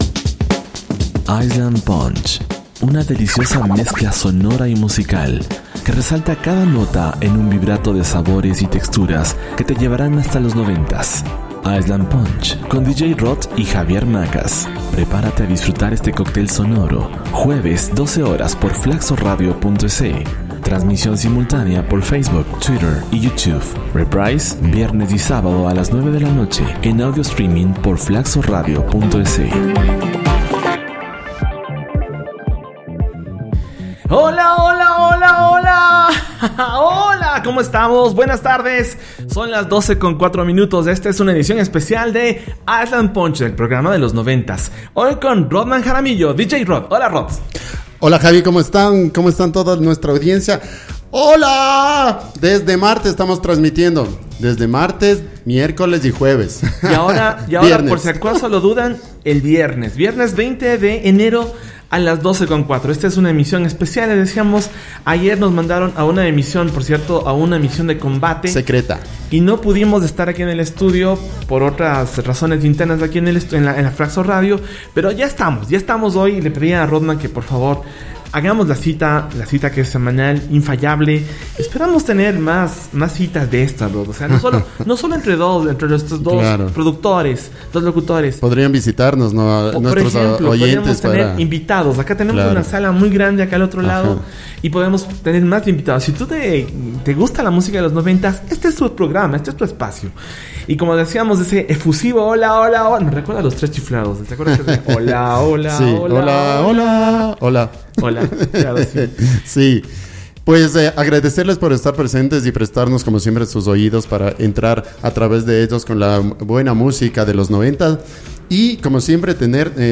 disfruta de la música de los 90tas